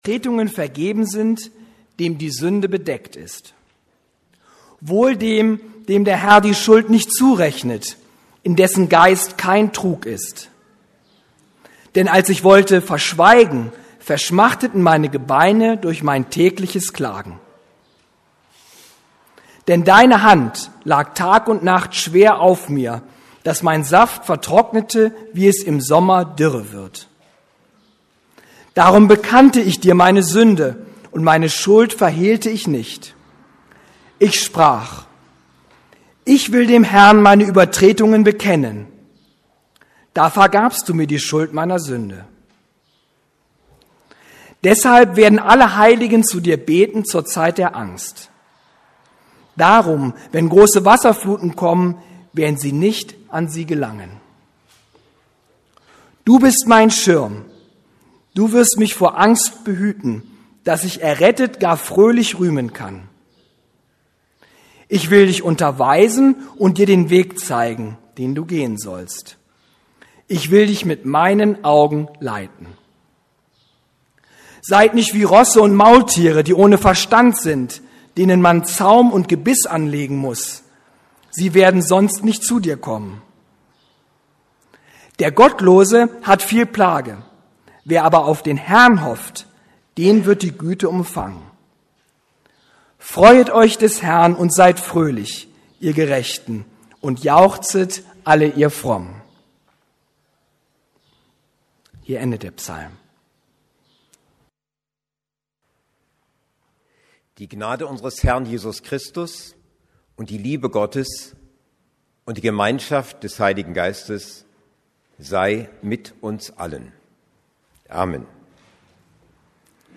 Als ich es verschweigen wollte... ~ Predigten der LUKAS GEMEINDE Podcast